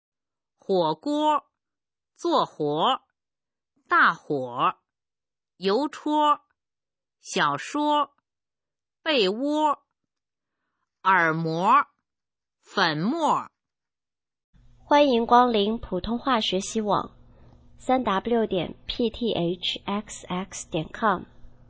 普通话水平测试用儿化词语表示范读音第23部分　/ 佚名